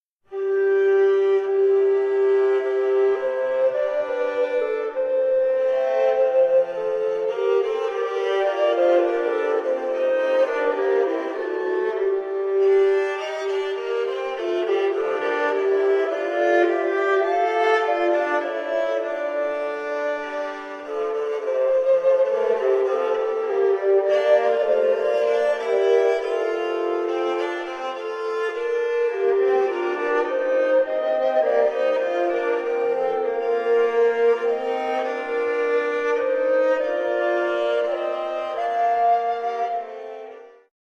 Współczesne prawykonania średniowiecznych utworów z Wrocławia, Cieszyna, Środy Śląskiej, Głogowa, Brzegu, Henrykowa, Żagania, kompozytorów anonimowych, Nicolausa Menczelliniego, hymny i sekwencja o św.
kontratenor, lutnia
fidel
flety proste, pomort